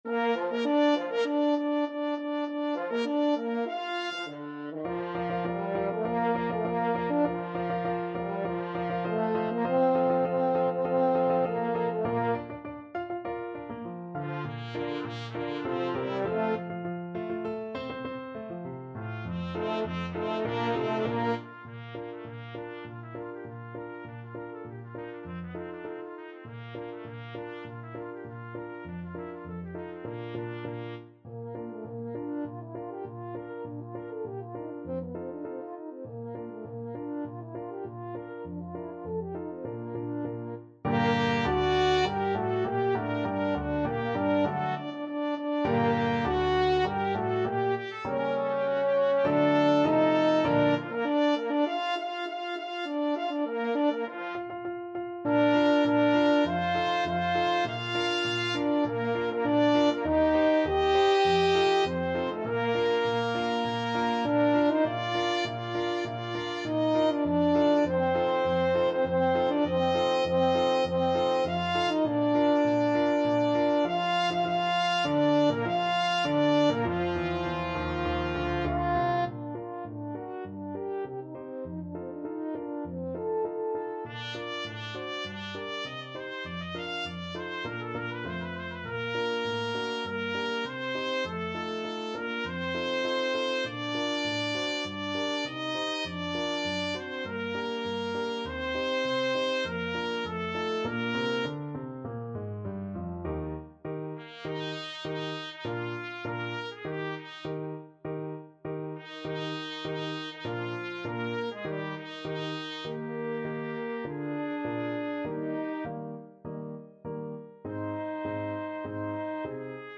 Sousa, John Philip - Flags of Freedom March(with piano)
Free Sheet music for Trumpet-French Horn Duet
2/4 (View more 2/4 Music)
Bb major (Sounding Pitch) (View more Bb major Music for Trumpet-French Horn Duet )
Classical (View more Classical Trumpet-French Horn Duet Music)